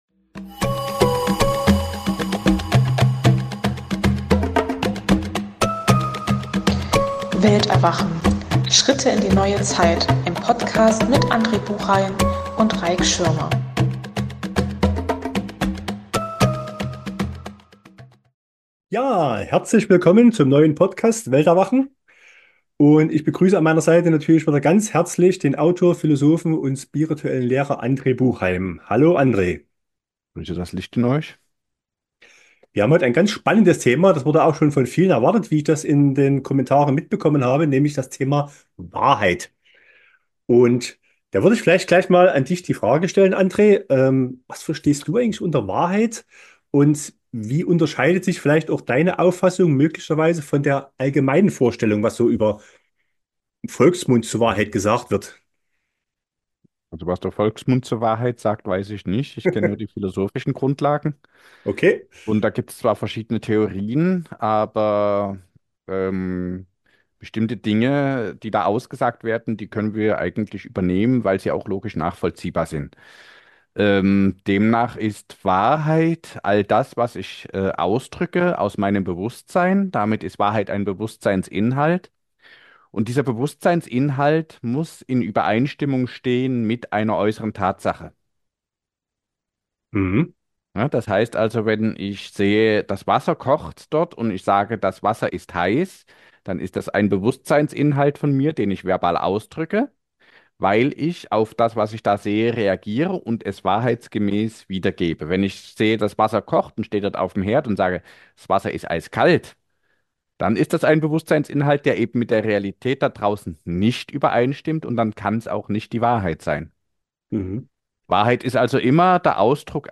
In einem lockeren Gespräch wird das Thema "Wahrheit" behandelt und die unterschiedlichsten Meinungen dazu erläutert.